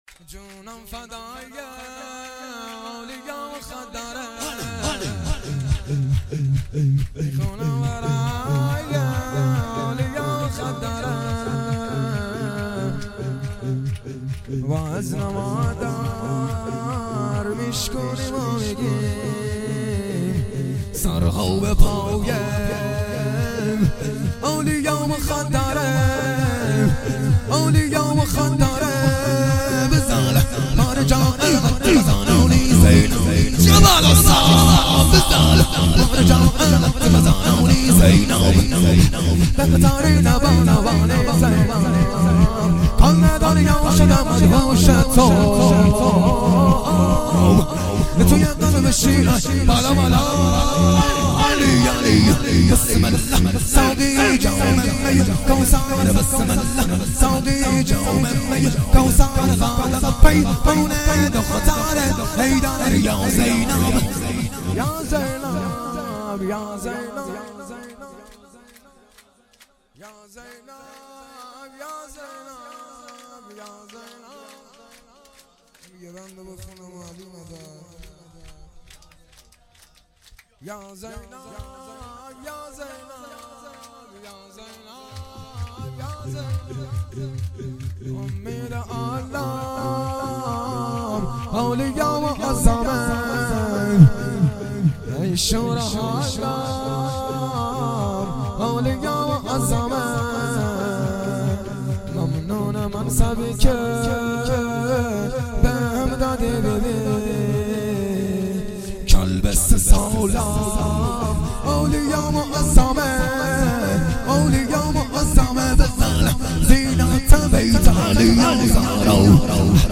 ولادت امام علی ۱۳۹۸